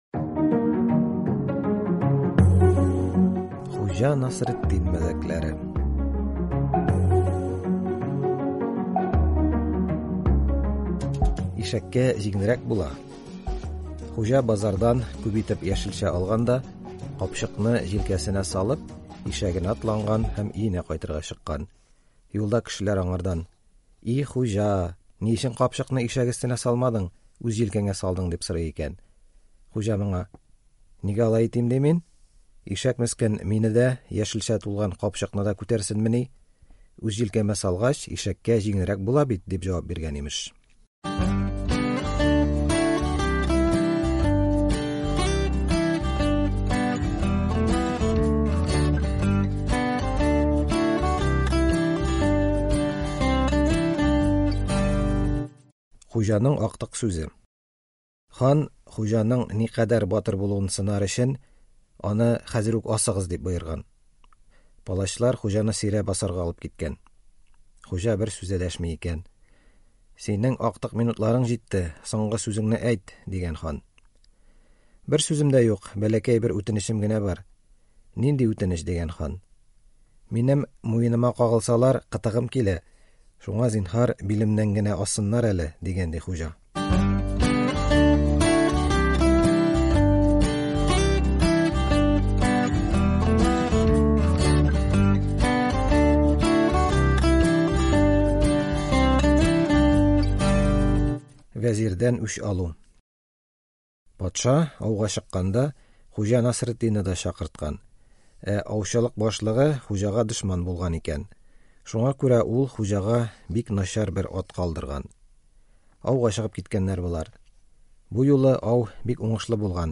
Читаем на татарском известные притчи об известном Ходже Насреддине! Это интересные анекдоты на тему средневекового быта.